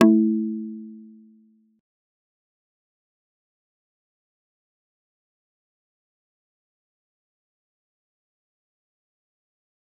G_Kalimba-A3-pp.wav